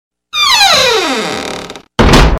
Suoneria Scary door sound
Sound Effects
scary , horror , door ,